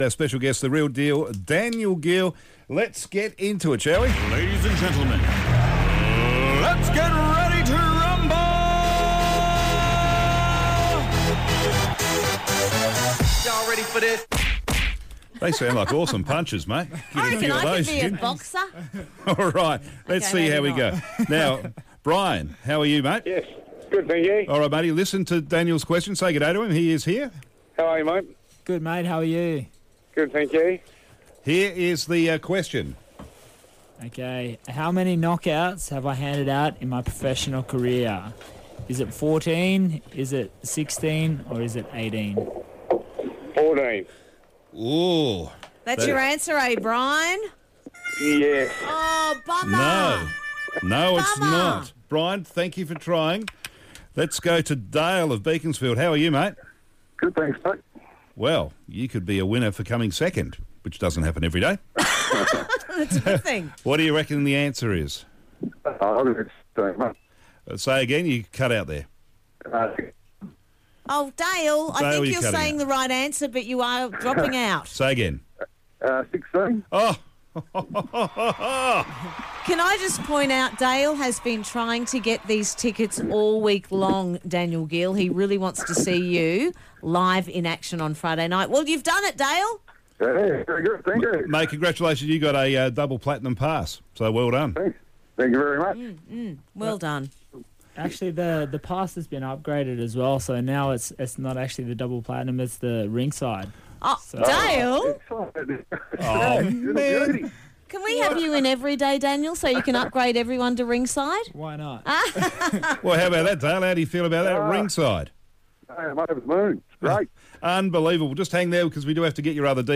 Interview - Daniel Geale 121016 Part2